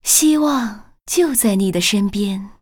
文件 文件历史 文件用途 全域文件用途 Dana_fw_03.ogg （Ogg Vorbis声音文件，长度2.6秒，102 kbps，文件大小：33 KB） 源地址:游戏语音 文件历史 点击某个日期/时间查看对应时刻的文件。 日期/时间 缩略图 大小 用户 备注 当前 2018年4月20日 (五) 02:45 2.6秒 （33 KB） 地下城与勇士  （ 留言 | 贡献 ） 源地址:游戏语音 您不可以覆盖此文件。